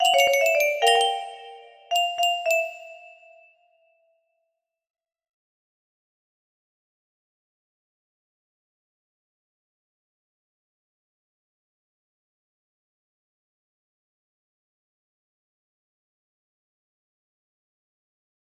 dasies music box melody